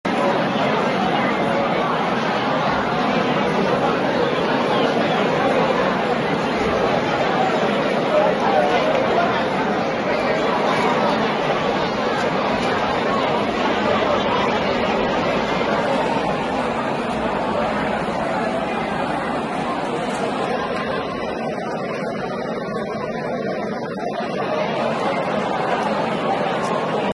È quanto accaduto tra venerdì e sabato in via Cambini, dove intorno all’una di notte la strada si presentava piena di persone. Un video girato da alcuni residenti documenta la situazione: la via affollata e un brusio continuo che risuona tra i palazzi, rendendo difficile il riposo per chi abita nella zona.
L’audio che ascoltate racconta una realtà che, secondo chi vive nel quartiere, si ripete ormai da tempo: cori, conversazioni ad alta voce, risate e musica che si protraggono fino a notte inoltrata.
audio-via-cambini.mp3